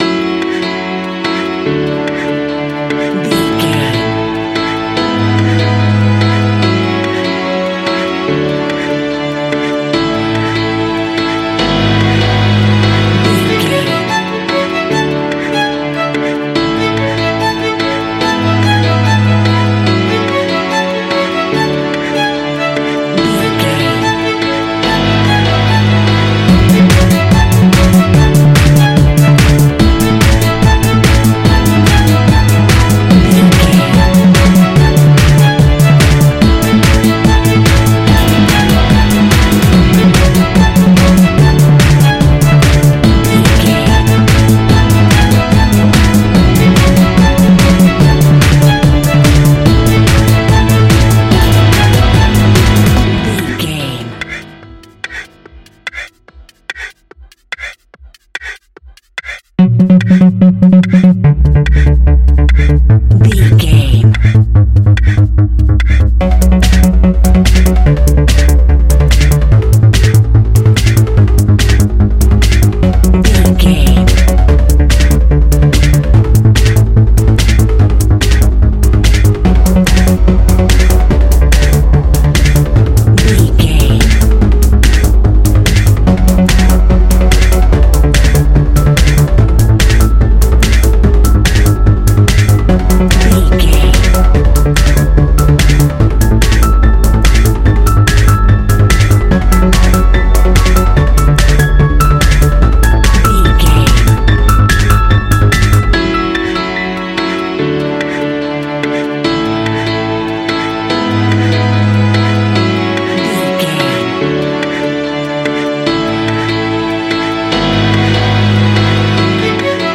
Ionian/Major
energetic
uplifting
hypnotic
drum machine
synthesiser
violin
piano
acid house
electronic
synth leads
synth bass